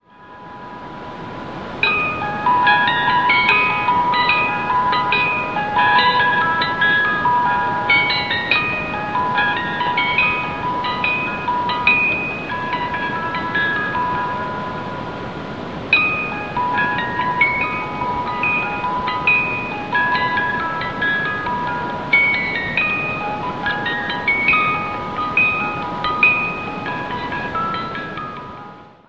Cajita musical con el tema El Lago de los Cisnes